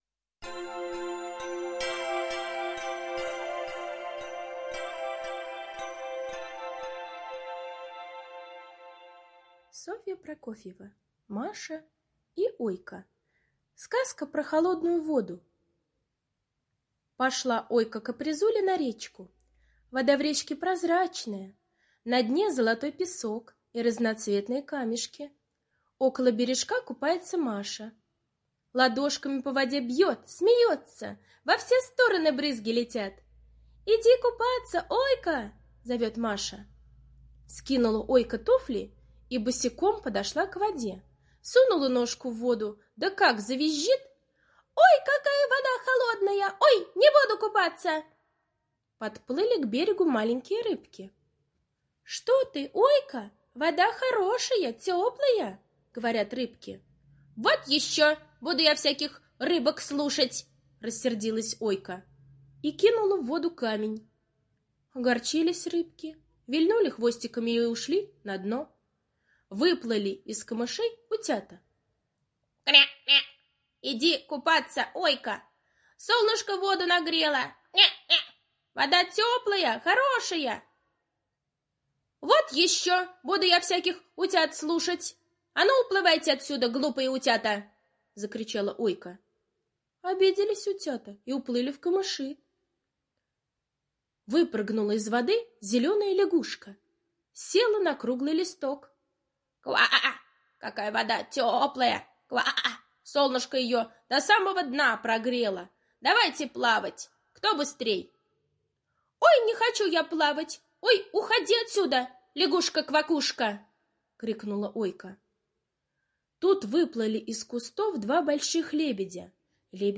Сказка про холодную воду - аудиосказка Прокофьевой С. Сказка о том, как Ойка боялась в речке купаться, потому что вода холодная.